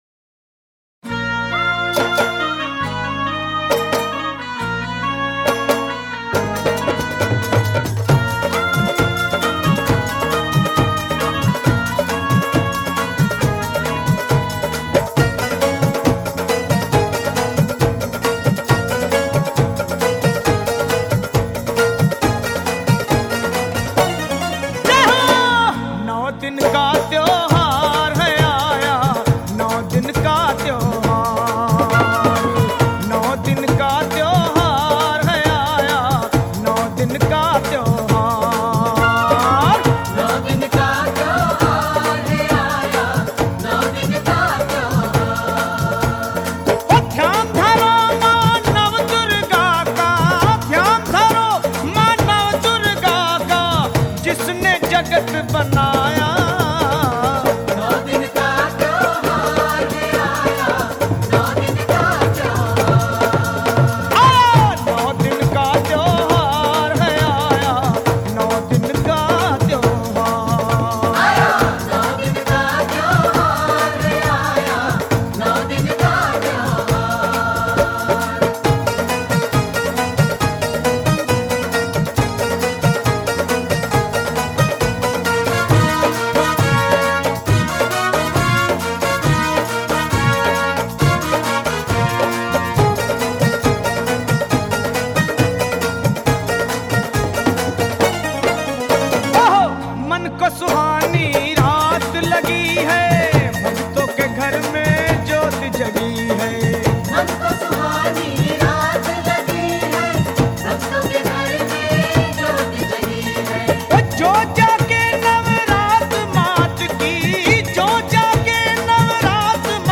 Online MATA bhajans
Navratri Special bhajan